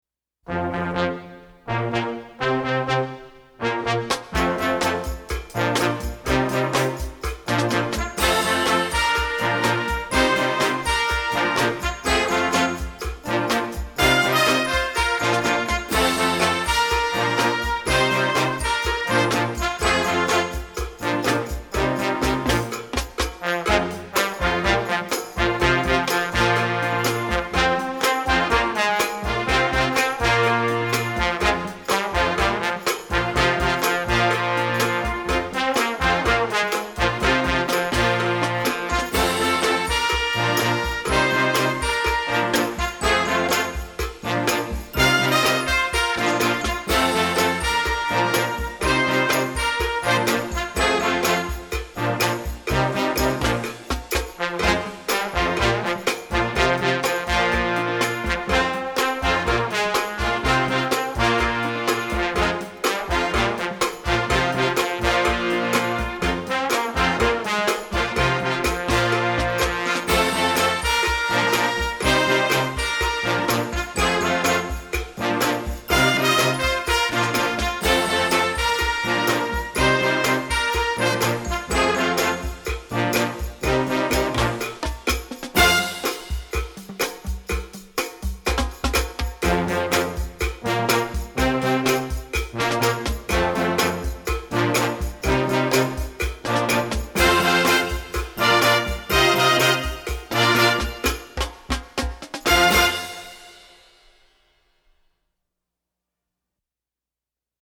Gattung: Moderner Einzeltitel
Marching-Band
Besetzung: Blasorchester